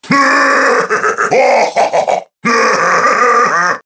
One of Bowser's voice clips in Mario Kart 7